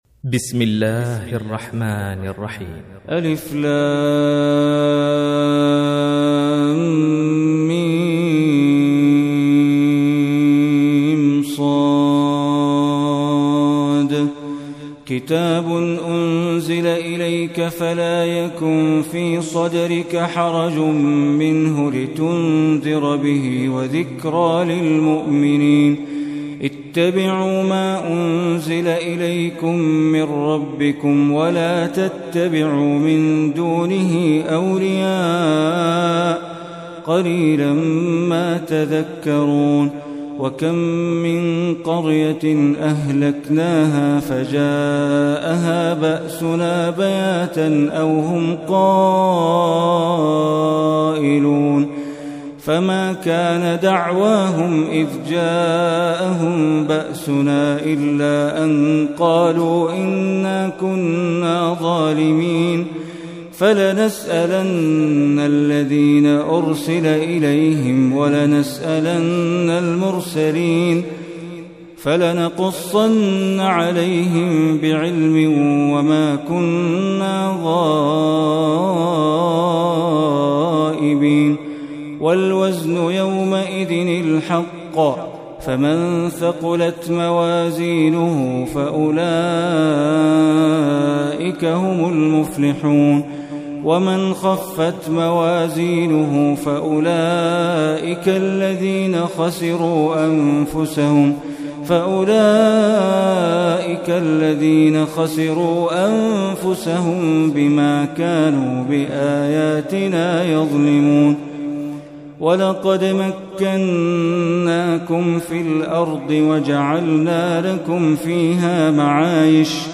Surah Al-Araf Recitation by Sheikh Bandar Baleela
Surah Al-Araf, listen or play online mp3 tilawat / recitation in Arabic in the beautiful voice of Sheikh Bandar Baleela.